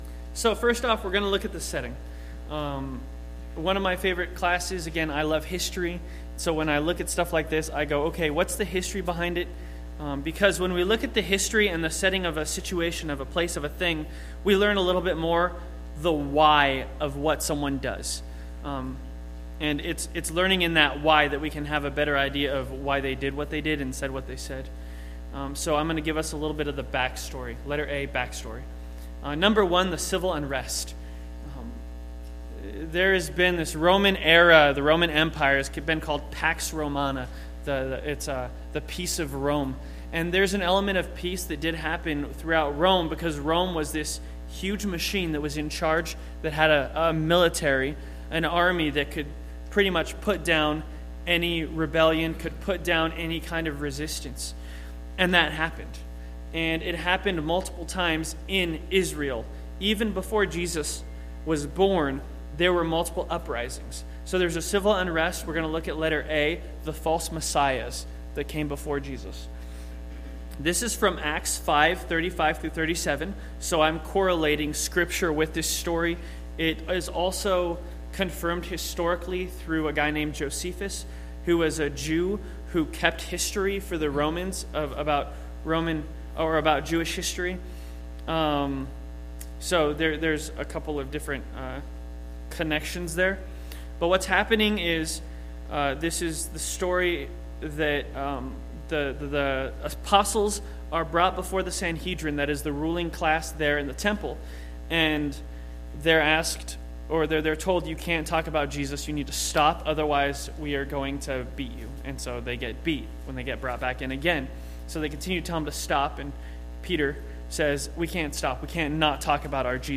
Sermons Archive - North Mason Bible Church